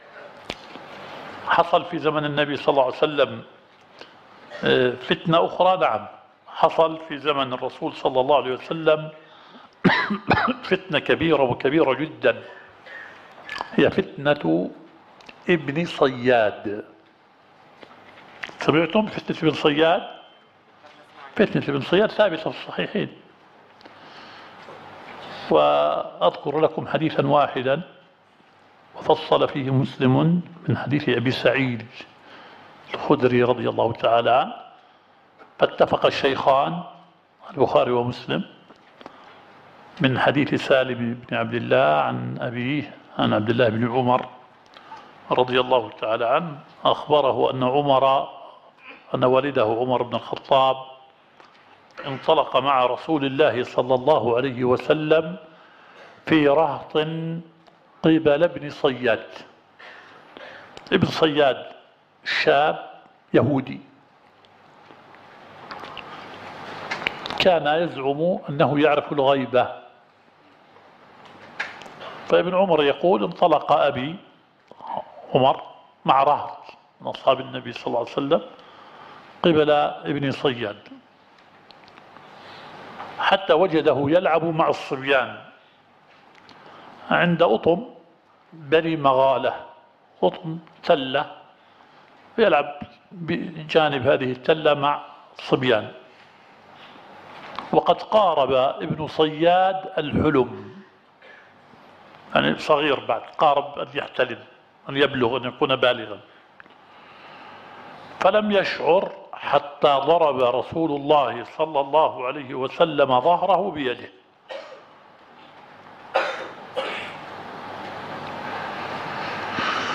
الدورة الشرعية الثالثة للدعاة في اندونيسيا – منهج السلف في التعامل مع الفتن – المحاضرة الثانية.